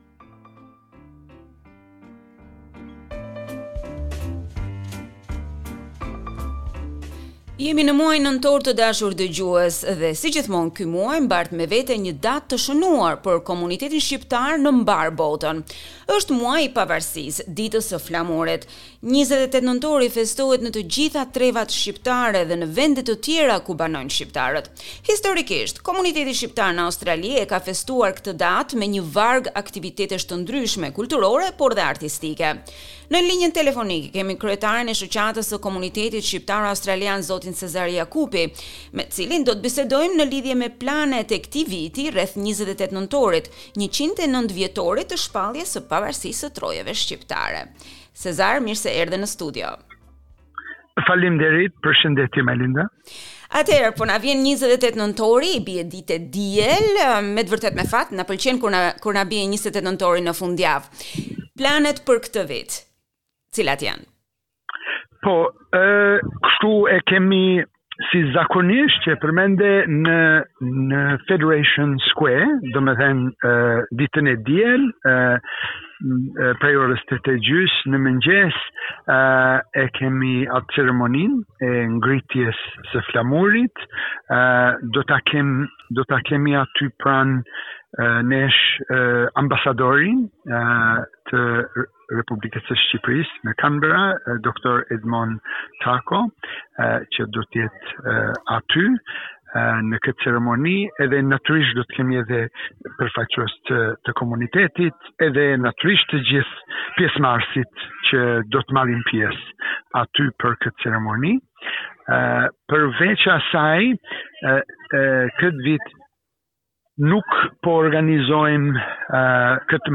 interview_national_day_final.mp3